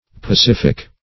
pacific \pa*cif"ic\, a. [L. pacificus: cf. F. pacifique.